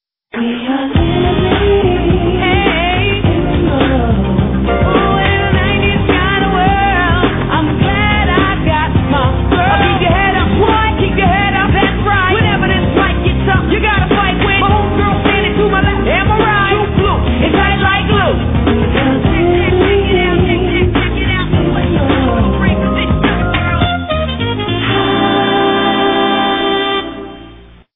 Tags: TV Songs actors theme song show